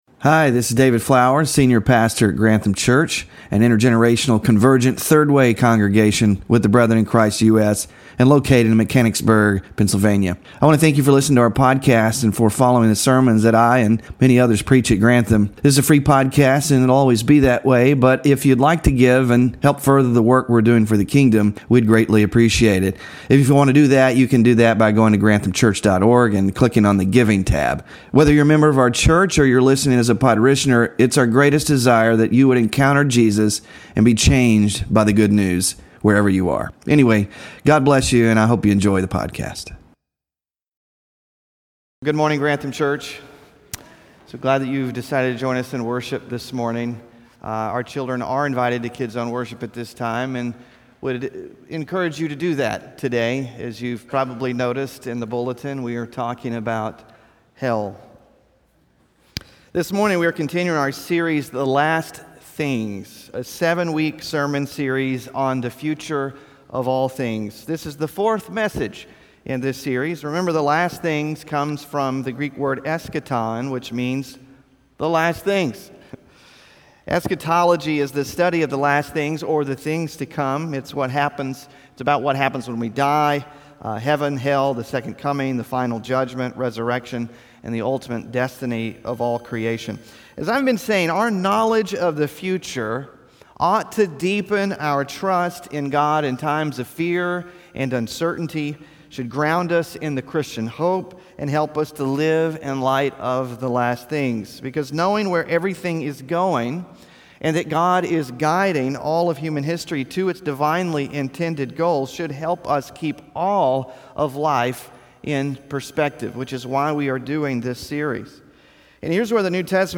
WORSHIP RESOURCES DEATH AFTER DEATH: REIMAGINING HELL - SERMON SLIDES 4 of 7 (5-25-25) SMALL GROUP QUESTIONS (5-25-25) BULLETIN (5-25-25) WHAT HAPPENS AFTER DEATH?